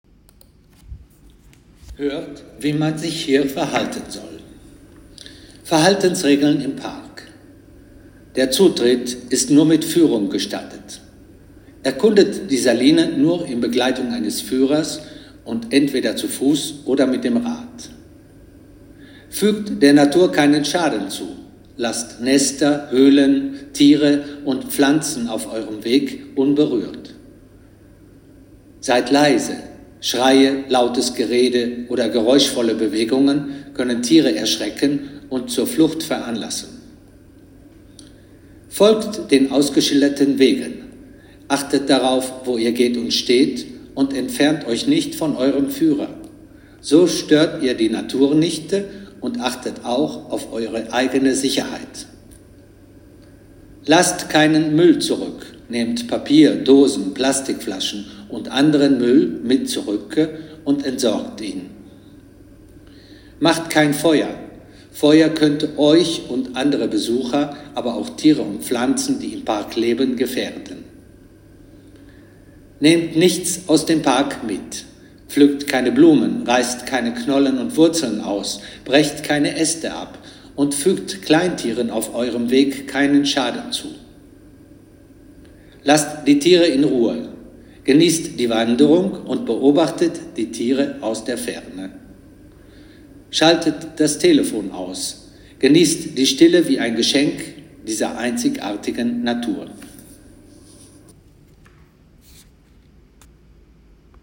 Audioguides auf Deutsch - Salina di Comacchio